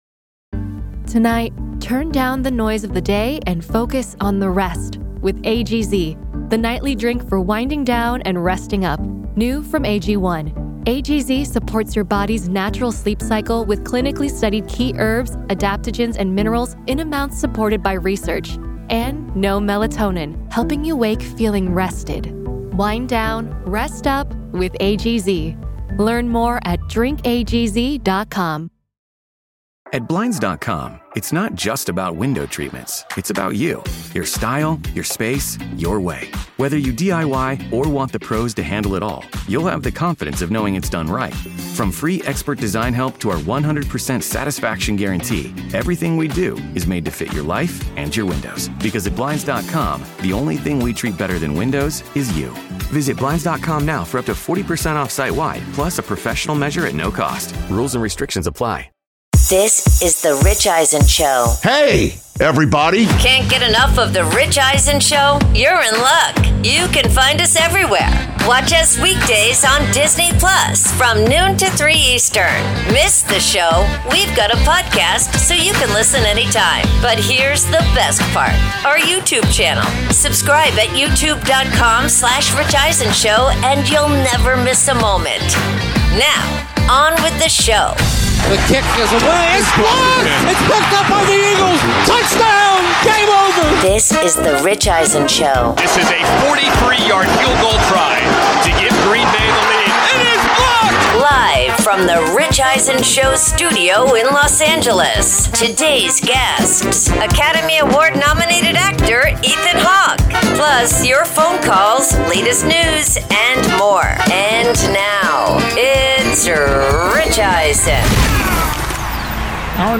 Hour 3: Ethan Hawke In-Studio, Chargers atop AFC West, Nick Bosa’s Torn ACL